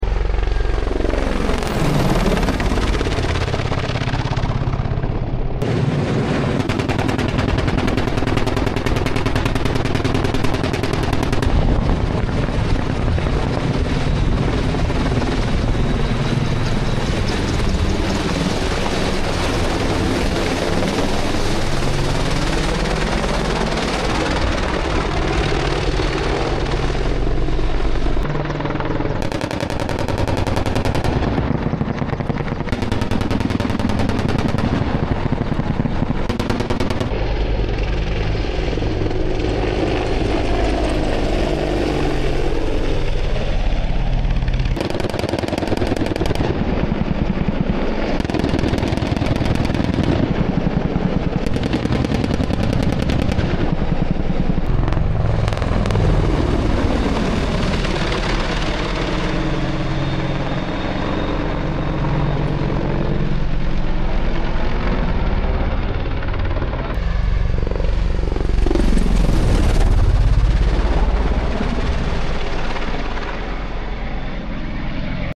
Apache in action Estonian sound effects free download
Apache in action Estonian Defence Forces Joint Terminal Attack Controllers conduct a close-air support live-fire exercise at Satur Range in Erbil, Iraq.